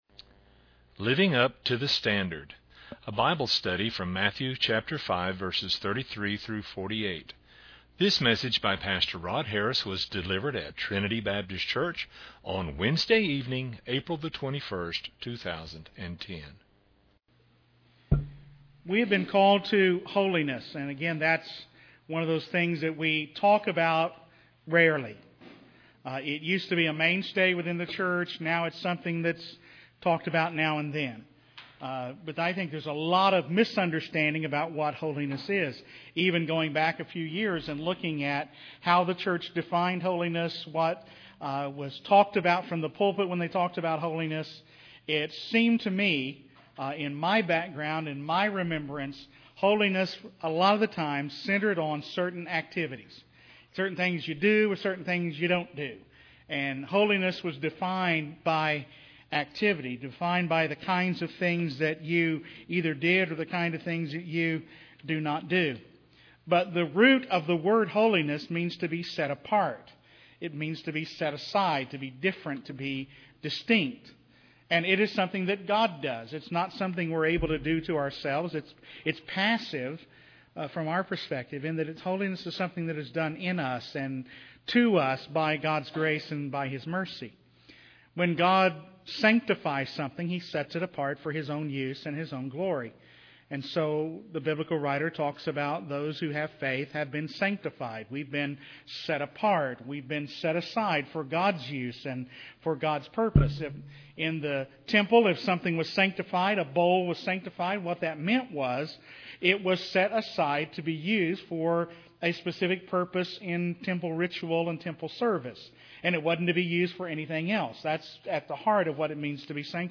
A Wednesday-evening Bible study from Matthew 5:33-48.